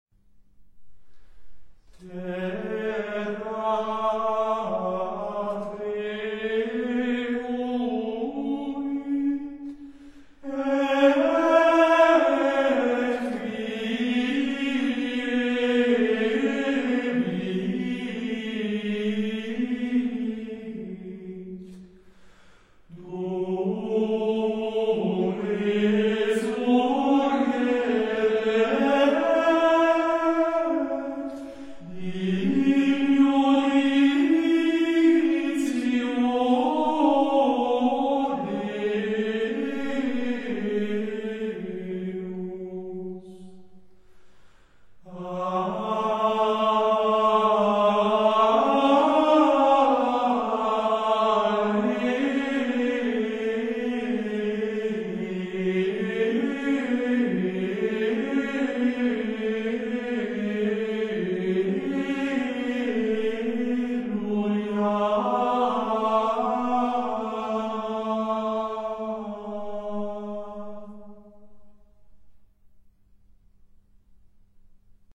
Ликование Пасхального времени в 20 старинных хоралах.